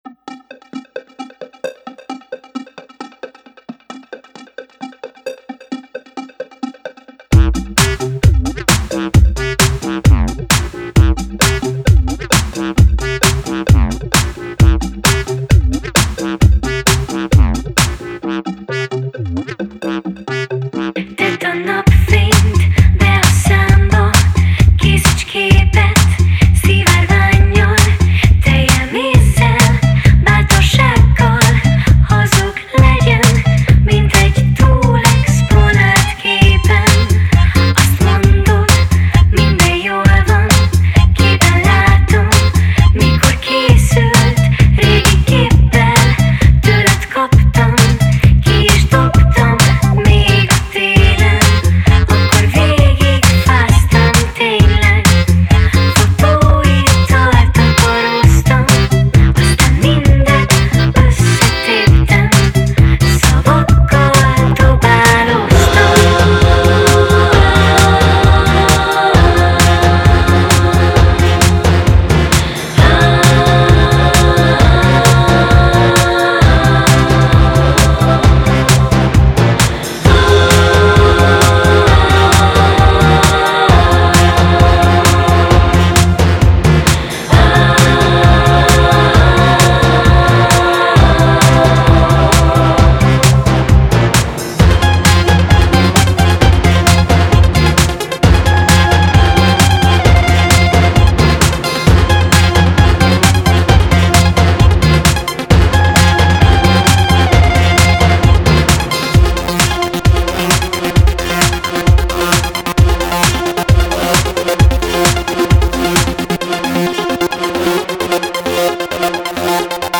an excellent electronic / alternative band